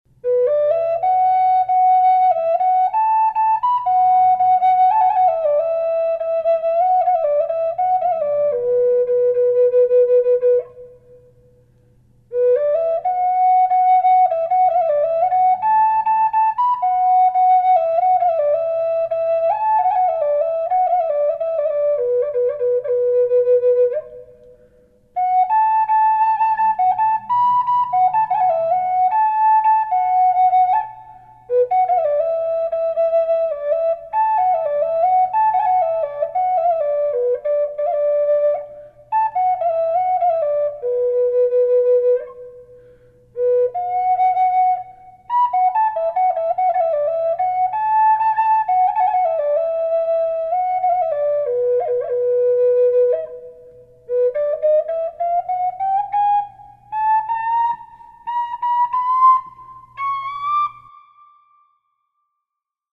Beautiful Oregon Port orford yellow Cedar red Hummingbird Flute in mid Bm.
Gorgeous voice & tone.
Sample Reverb added
port-orford-high-B-reverb.mp3